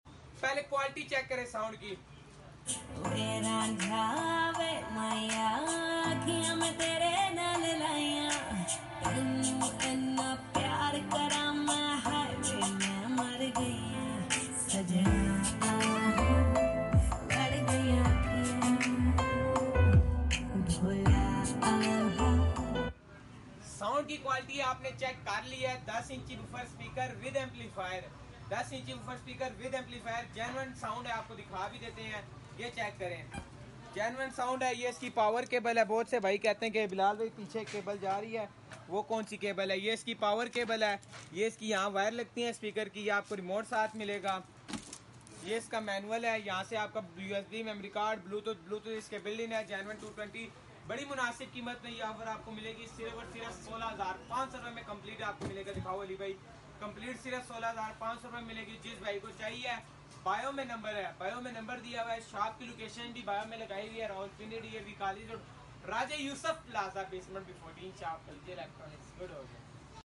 10 Inches Woofer Speaker With Sound Effects Free Download